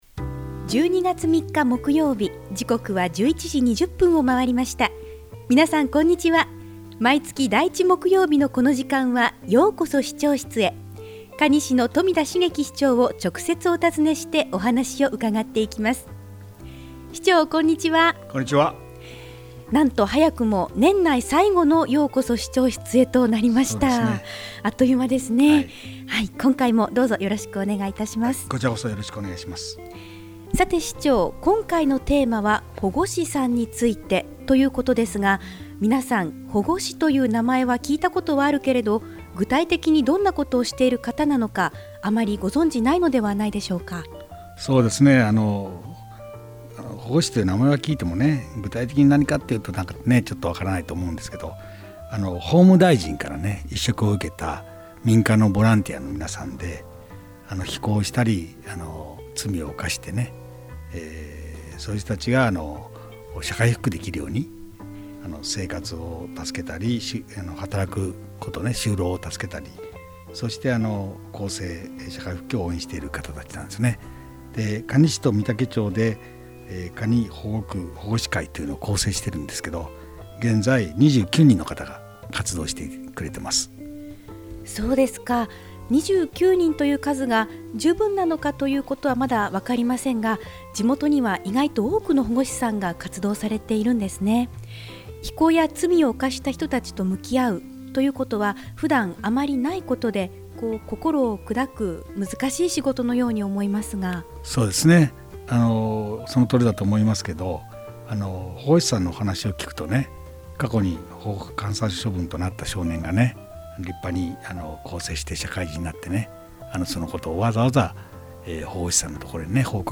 コミュニティFM放送局「FMらら」のようこそ市長室へのページです。
ようこそ市長室へ 2015-12-03 | ようこそ市長室へ 「ようこそ市長室へ」 可児市長室へ直接伺って、まちづくりの課題、魅力ある地域、市政情報などを 中心に、新鮮な情報を可児市長自ら、皆様にお届けする番組です。